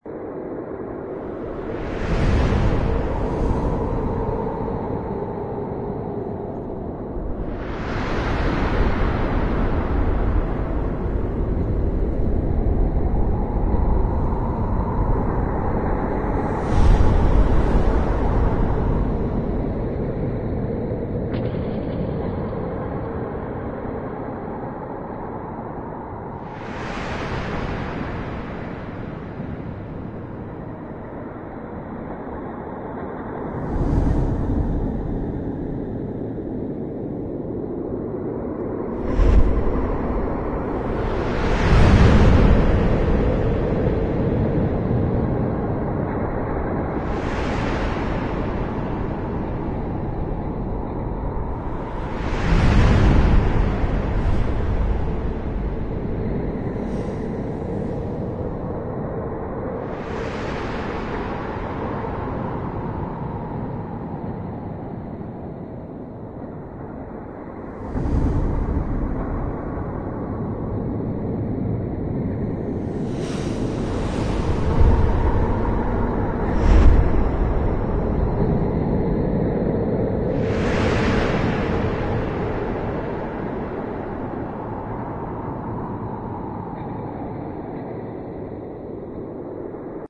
zone_nebula_crow.wav